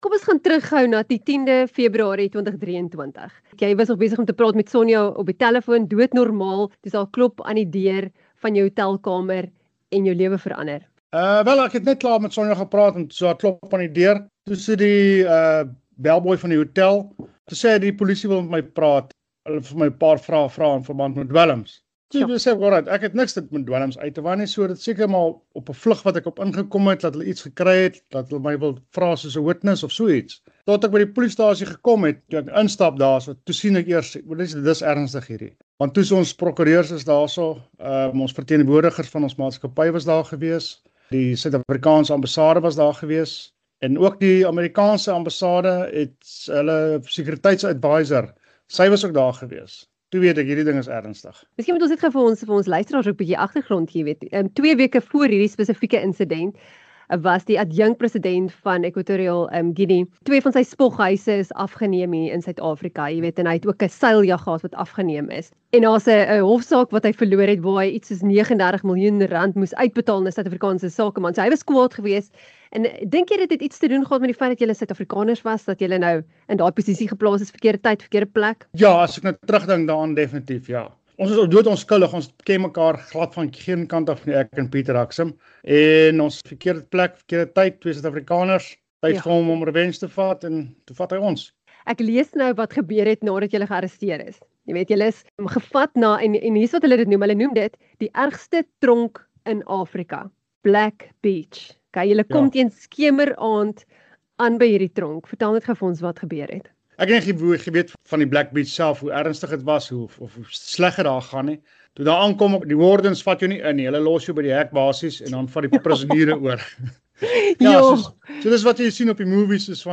Onderhoud